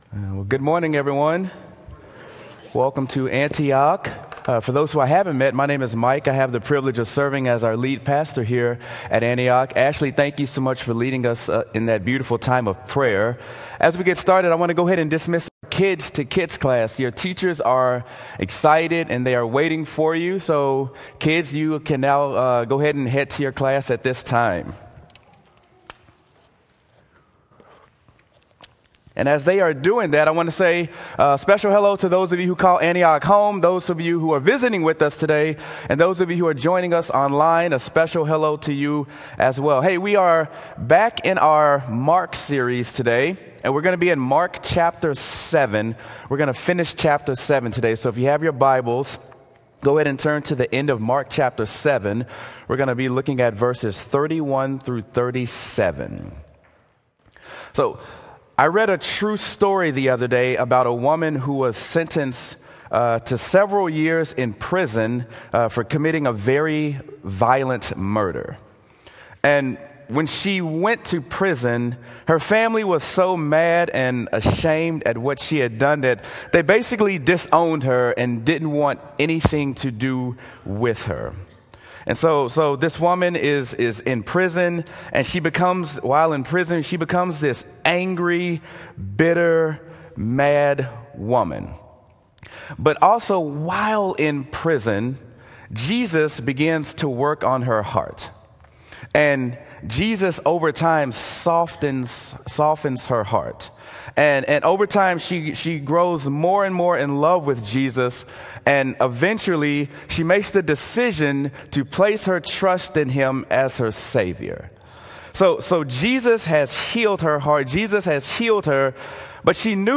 Sermon: Mark: I’ve Seen the Power of Jesus
sermon-mark-ive-seen-the-power-of-jesus.m4a